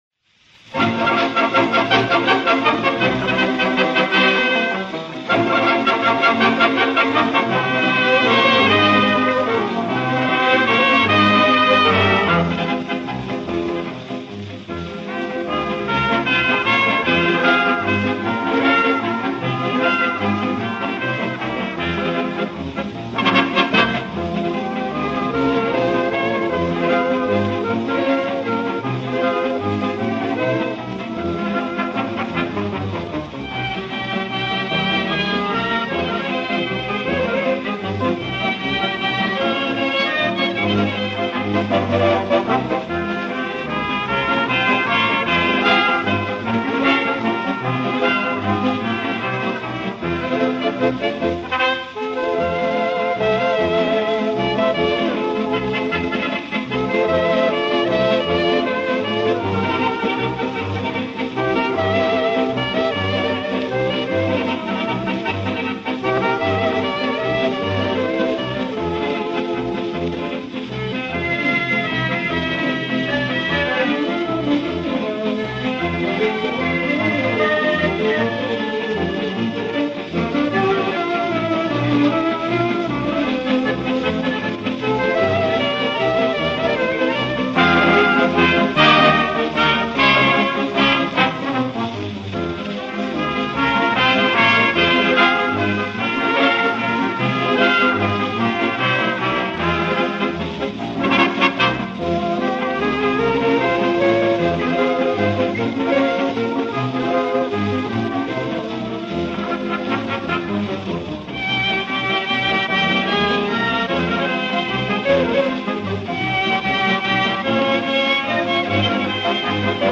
пасодобля